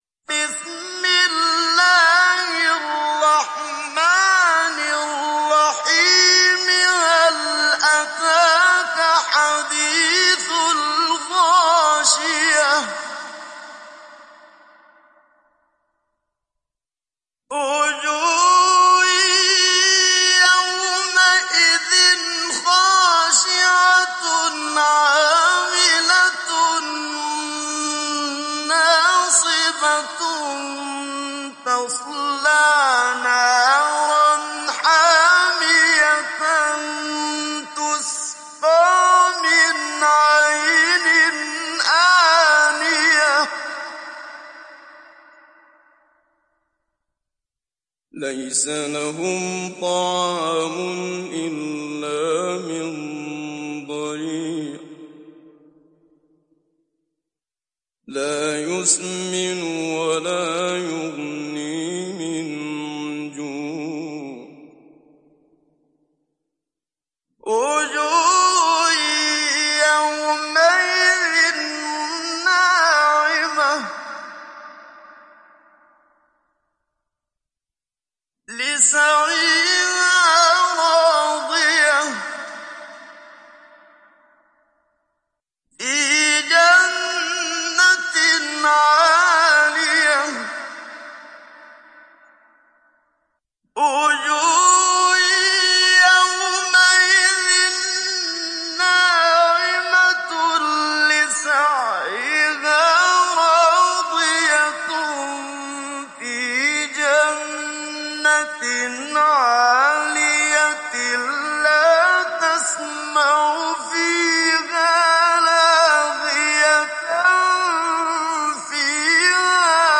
İndir Gaşiye Suresi Muhammad Siddiq Minshawi Mujawwad